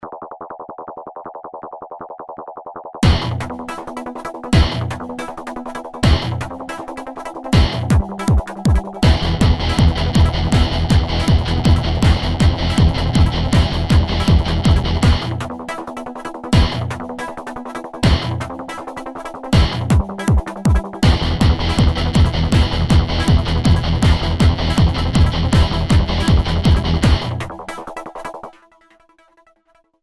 Чем-то тему DOOM напоминает.
Не знаю, что такое накладка, но пишу все и свожу в Cubase :)